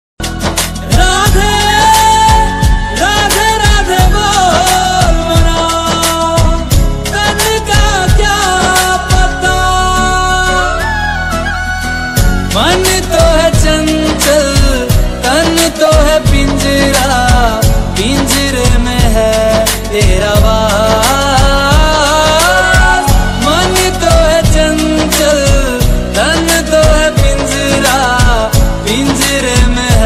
Bhakti Ringtone